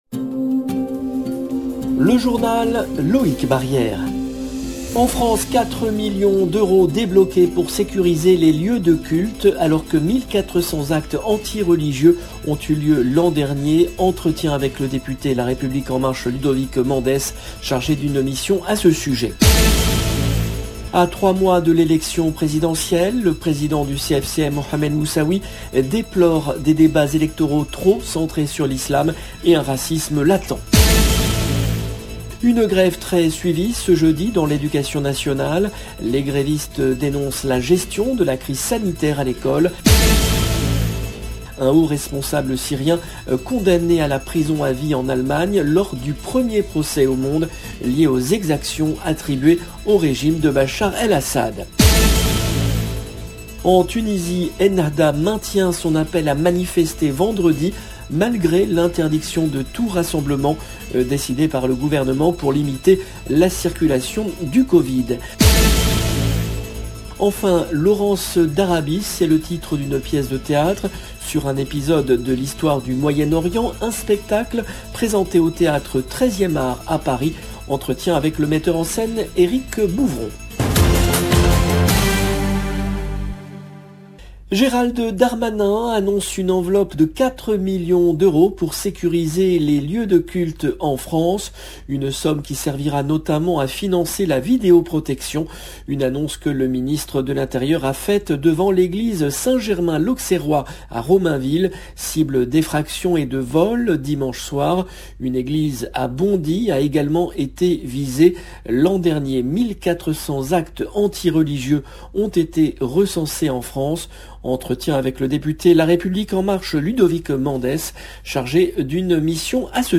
En France, 4 millions d’euros débloqués pour sécuriser les lieux de culte alors que 1400 actes anti-religieux ont eu lieu l’an dernier. Entretien avec le député LREM Ludovic Mendes chargé d’une mission à ce sujet.